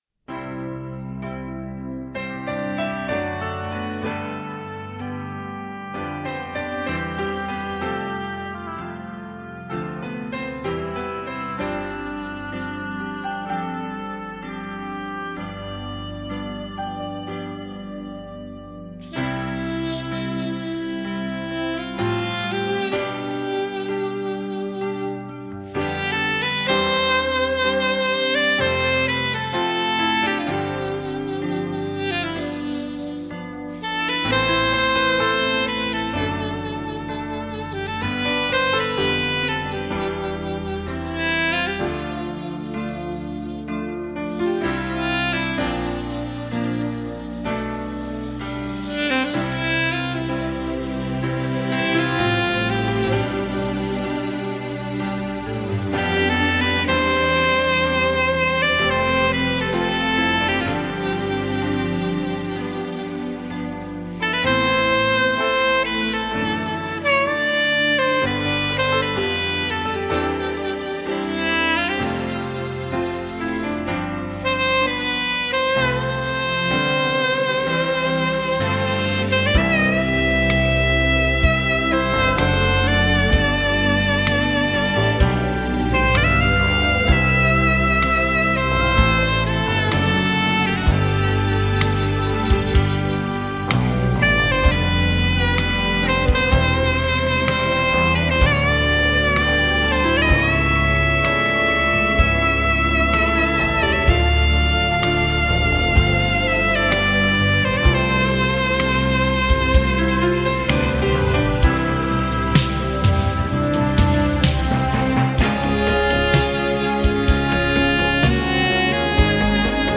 Dios_Tan_Solo_Dios_Sax.mp3